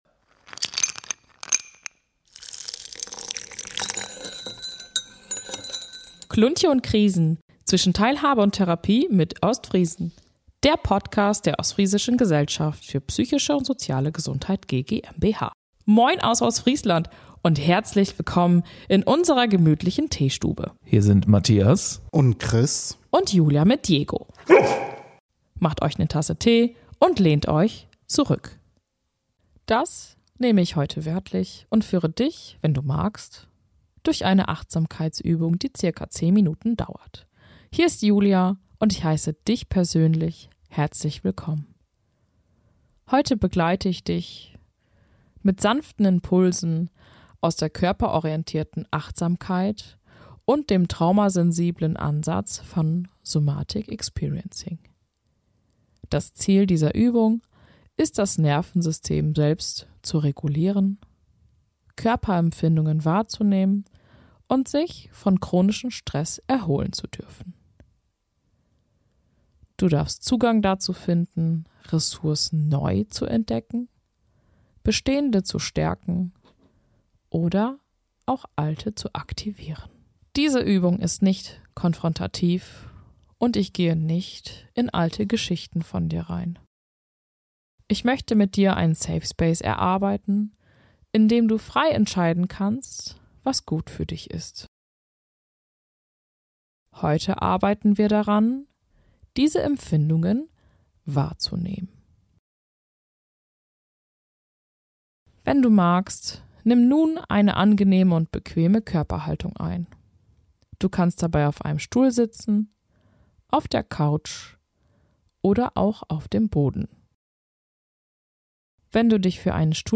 Folge 5 - prOGramm: Achtsamkeitsübung Somatic Experiencing ~ Kluntje & Krisen Podcast